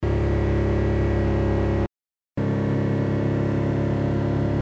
音声の収録は車内の運転席（顔の位置）で収録していますよ。
停車中4000回転時の車内音声
ノーマル時の騒音後に防音処理後の騒音が流れます
2.5dBほど音量が小さくなっています。